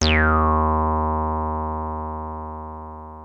303 D#2 9.wav